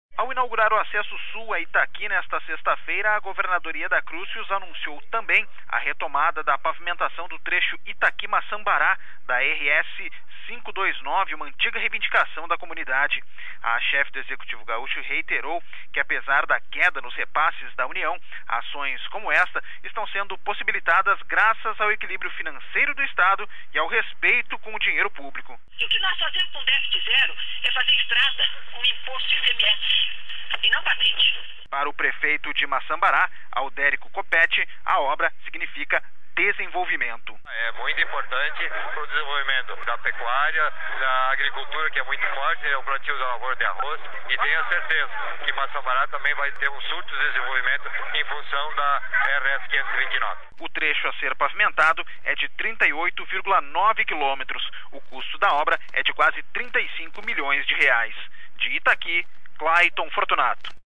O anúncio foi feito, nessa sexta-feira (29), pela governadora Yeda Crusius, em Itaqui. O trecho faz parte da RS-529.